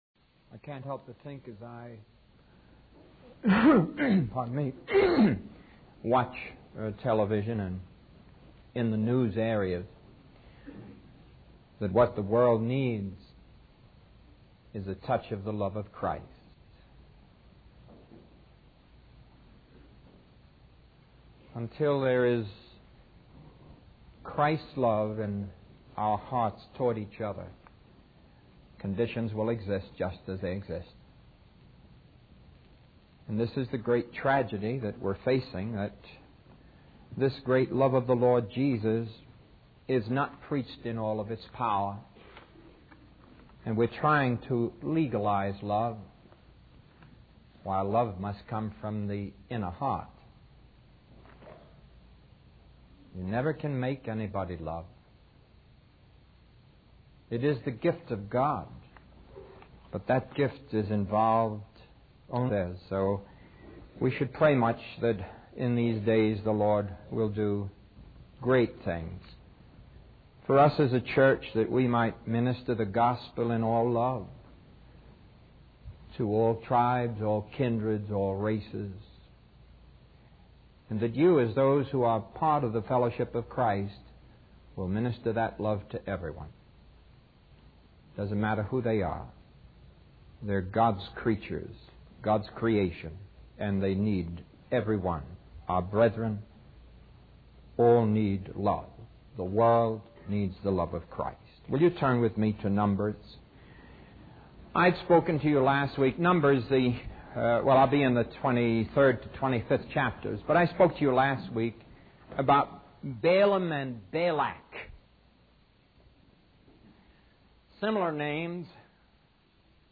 In this sermon, the preacher emphasizes the need for the love of Christ to be present in the world. He believes that until people have Christ's love in their hearts towards one another, the current conditions in the world will persist.